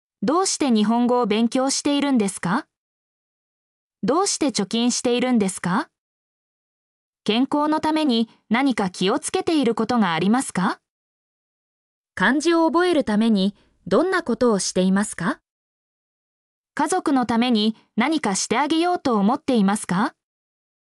mp3-output-ttsfreedotcom-7_QD3dqYoF.mp3